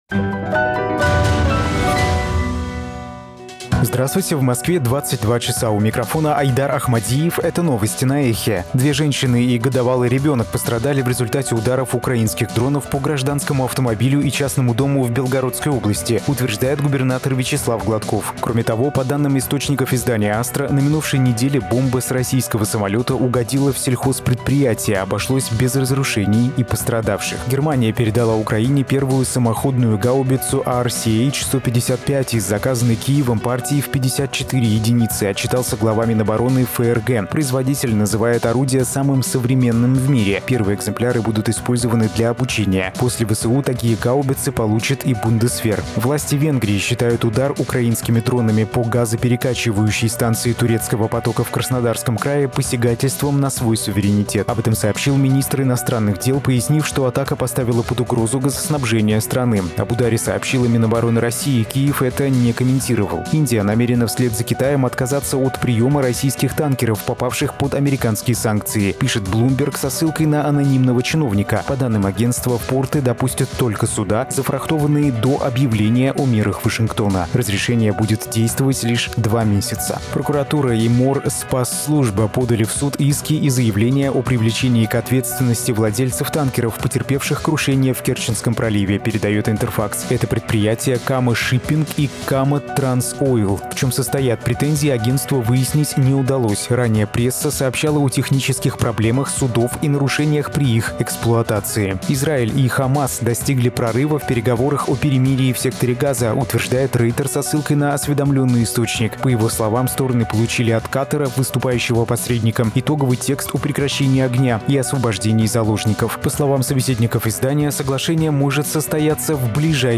Слушайте свежий выпуск новостей «Эха».